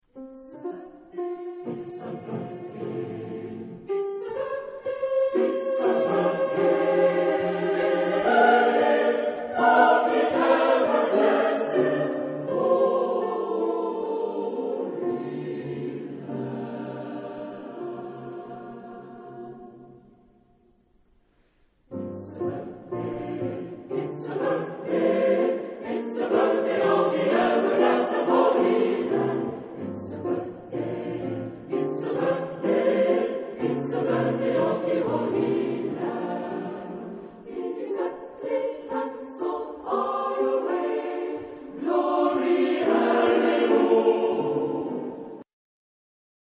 Genre-Style-Form: Contemporary ; Secular ; Popular
Mood of the piece: crisp ; jazzy ; joyous ; rhythmic
Type of Choir: SATB  (4 mixed voices )
Instrumentation: Piano
Tonality: F major